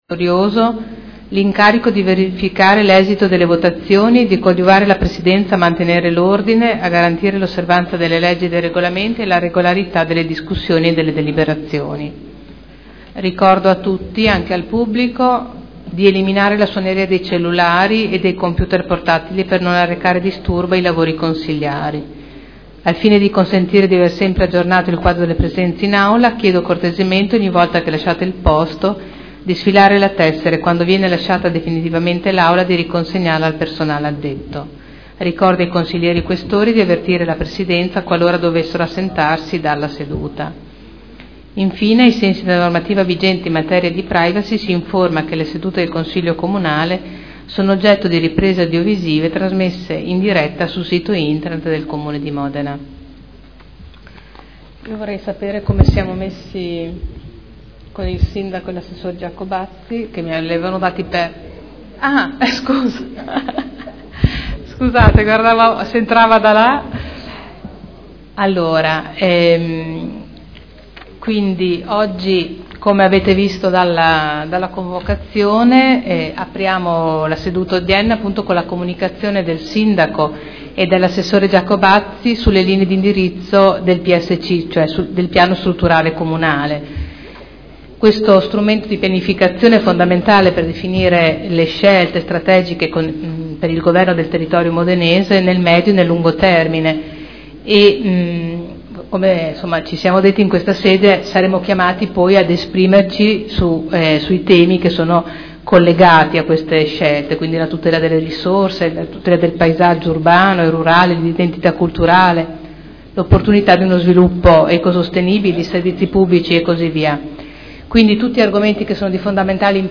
Presidente — Sito Audio Consiglio Comunale
Seduta del 01/10/2012. Apre i lavori del Consiglio.